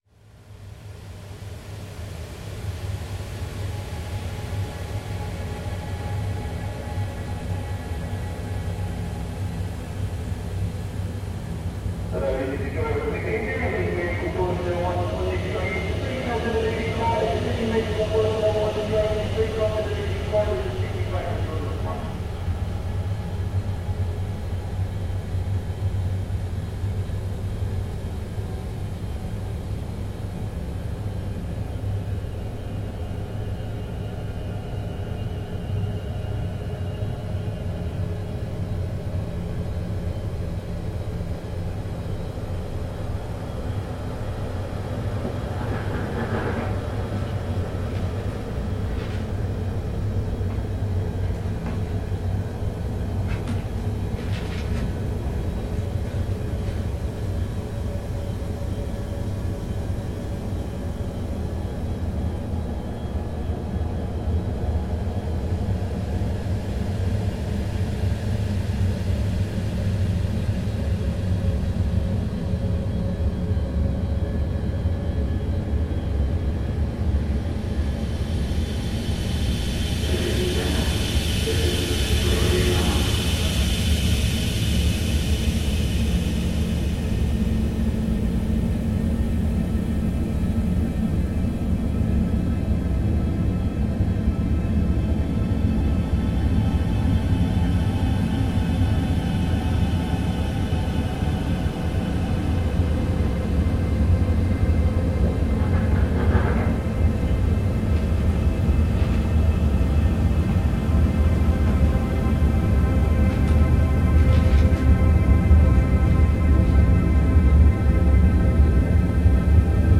Tube train field recording reimagined from the perspective of the sick passenger and how they might have experienced it.